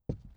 ES_Walk Wood Creaks 1.wav